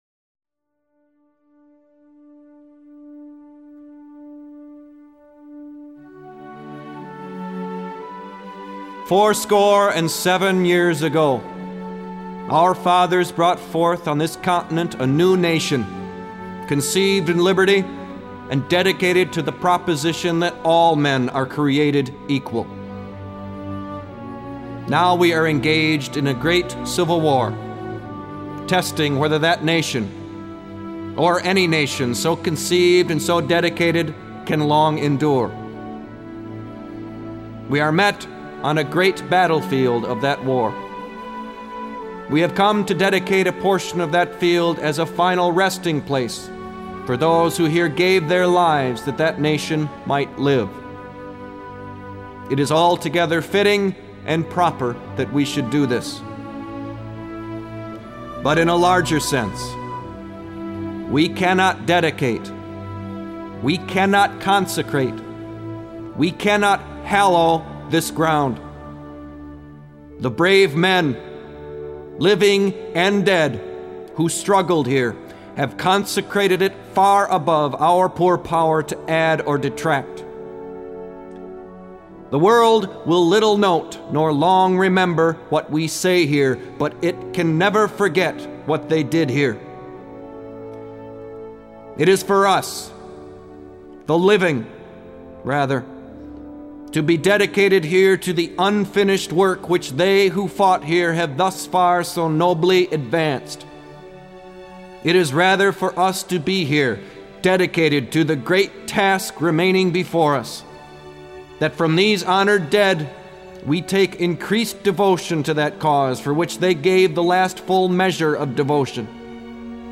Rede: "Gettysburg Address"
19 The Gettysburg Address.mp3